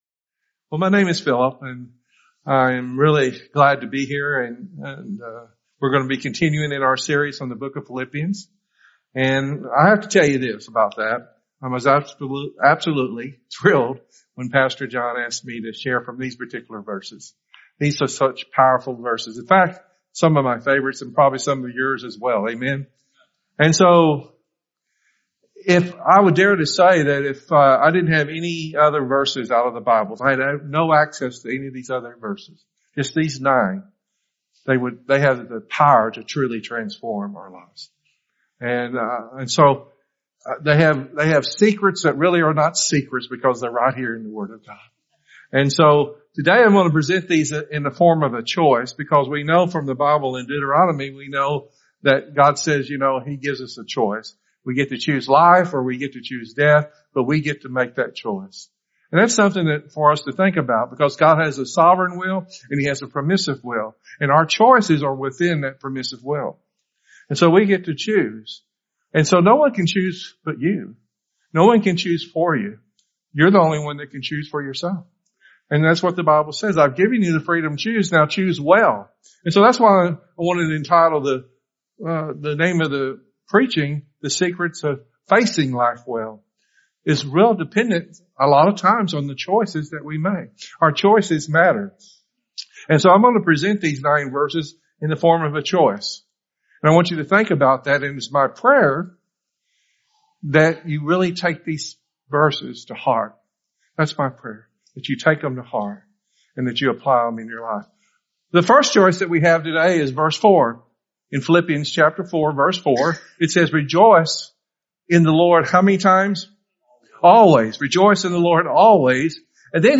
The Search Sermon